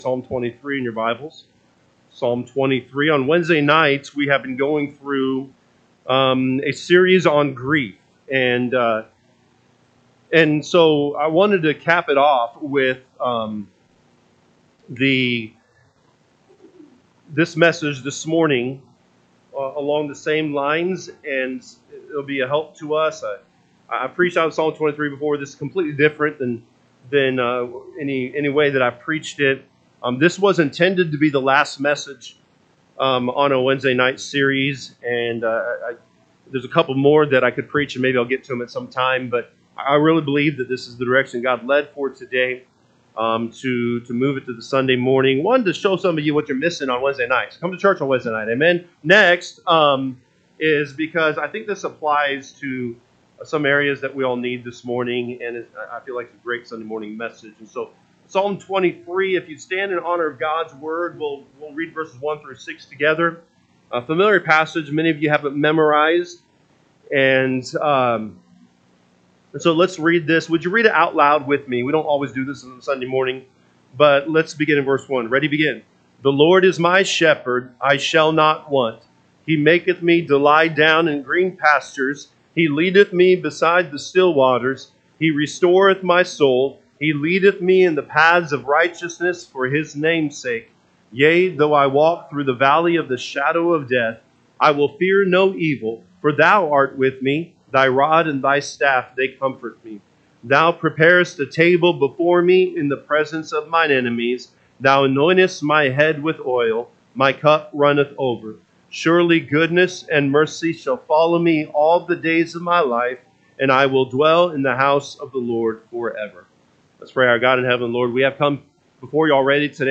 June 1, 2025 am Service Psalm 23 (KJB) 23 The Lord is my shepherd; I shall not want. 2 He maketh me to lie down in green pastures: he leadeth me beside the still waters. 3 …